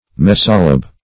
Search Result for " mesolabe" : The Collaborative International Dictionary of English v.0.48: Mesolabe \Mes"o*labe\, n. [L. mesolabium, Gr.